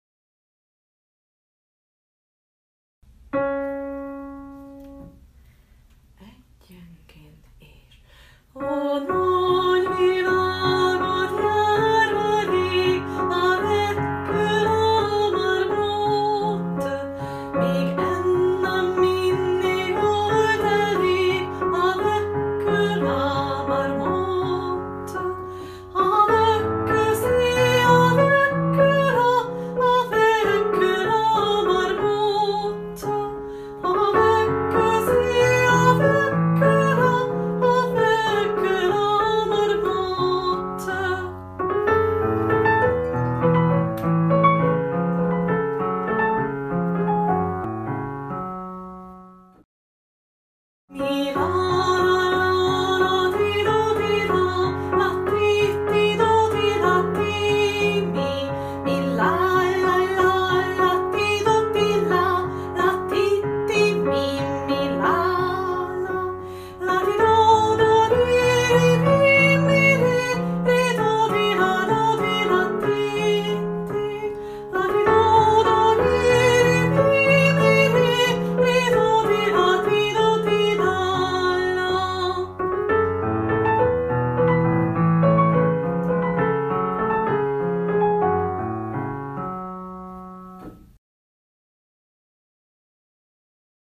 A változatosság kedvéért a felvételek otthon készültek. Vigyázat, nyomokban speciális effekteket (zajokat) tartalmazhatnak.